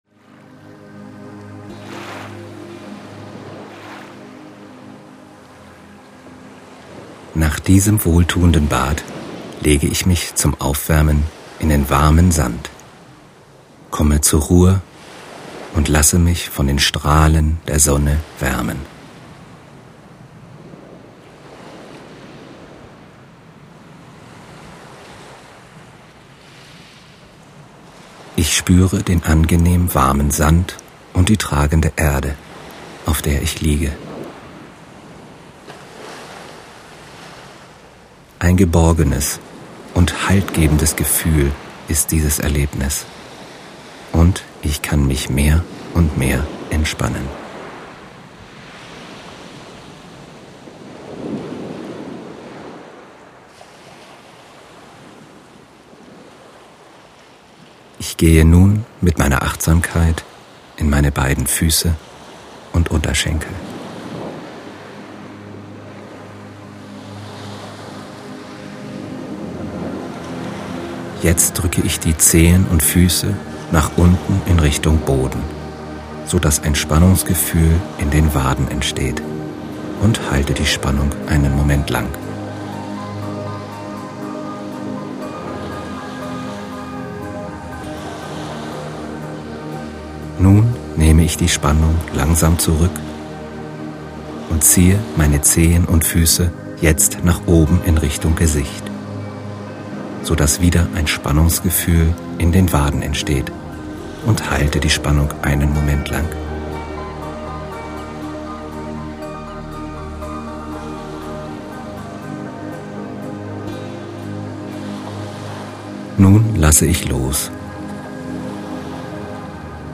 Der Klang des Meeres wird kombiniert mit einer sanften, wohlklingenden und eingängigen Entspannungsmusik. Die feine Abstimmung von Wort, Musik und Naturgeräuschen verstärkt die beruhigende Wirkung auf Ihren Geist und Körper.
Das Meer und die Musik erklingen nach der Entspannungsübung lange weiter, halten Sie in Ihrem persönlichen Entspannungszustand und lassen Sie, wenn Sie nicht schon in Morpheus Armen ruhen, sanft und schwerelos einschlafen.
Audio Auszug / Vorschau Leichter Einschlafen mit Phantasiereise und Progressiver Muskelentspannung nach Jacobson
syncsouls_leichter_einschlafen_fantasiereise_progressive_muskelentspannung.mp3